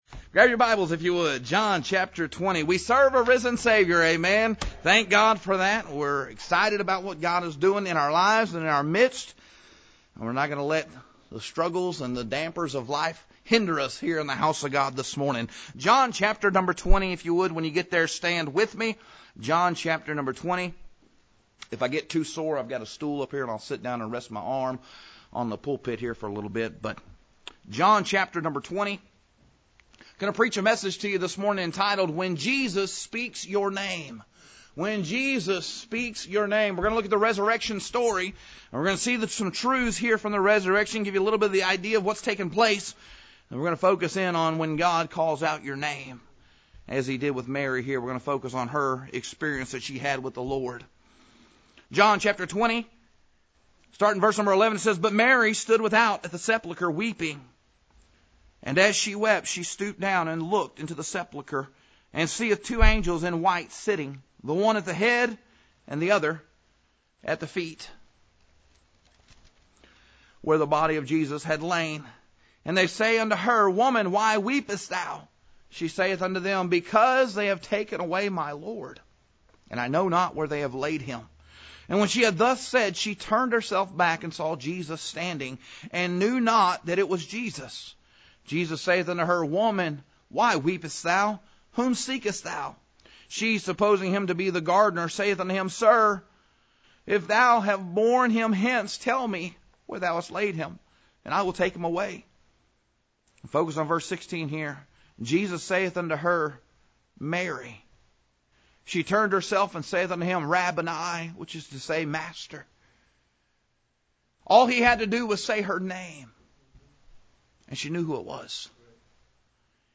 This sermon examines John 20:11–18 through the eyes of Mary Magdalene so we can learn how Jesus calls, comforts, corrects, commissions, and calms His people. The focus is simple but profound: when Jesus speaks your name, He speaks into the deepest needs of your heart — whether for salvation, surrender, service, correction, fellowship, or serenity.